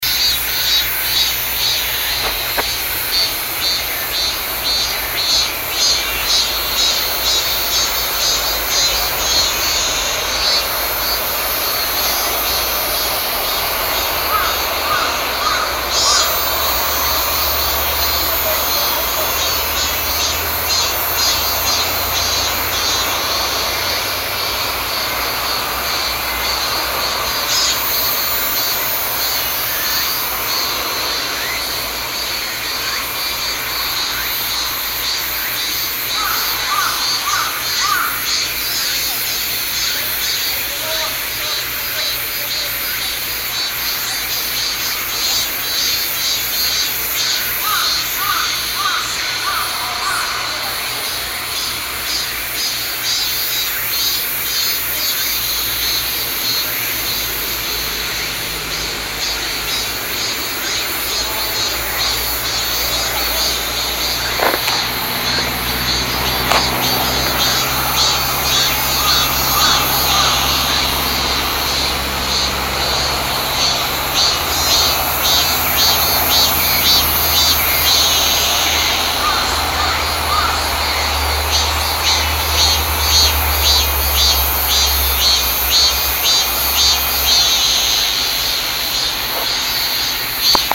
이제 길 따라 내려가면 땅고개입니다.
내림길 양쪽 숲에서는 종류가 다른 매미들이 지나가는 여름이 아쉬운지 합창소리가 요란합니다